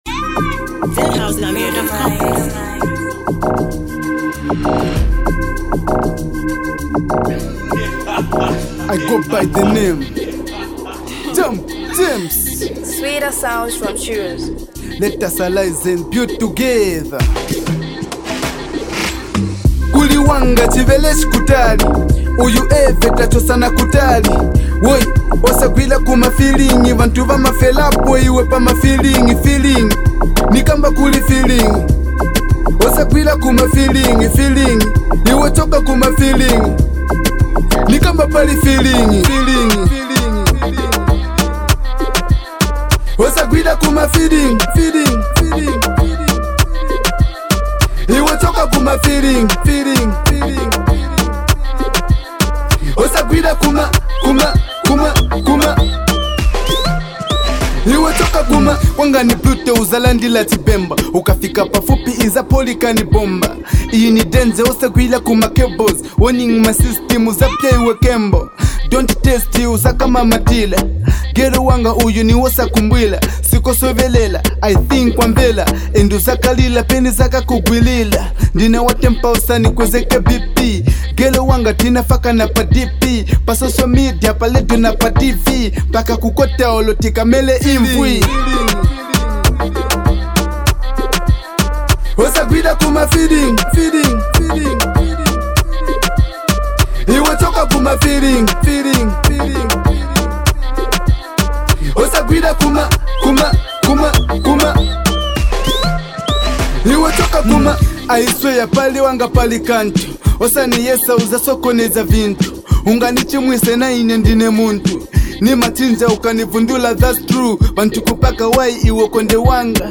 bringing raw rap energy and bold vibes.
With hard-hitting lyrics, confident flow, and a catchy beat